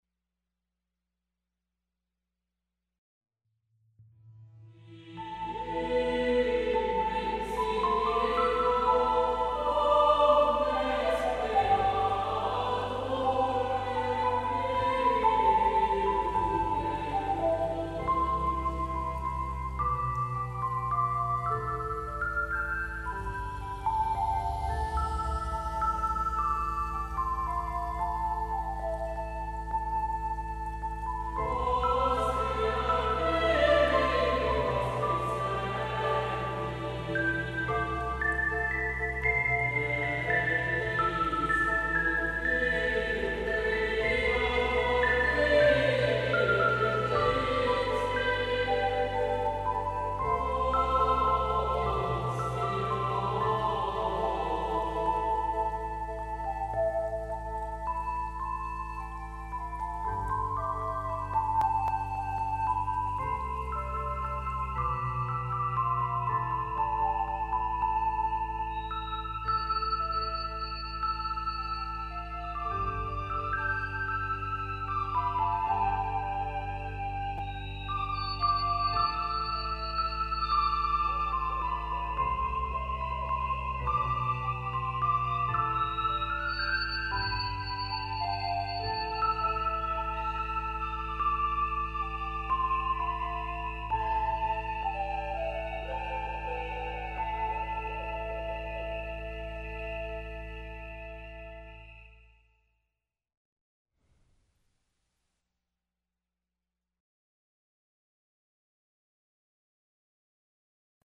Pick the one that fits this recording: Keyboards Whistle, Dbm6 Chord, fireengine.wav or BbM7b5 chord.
Keyboards Whistle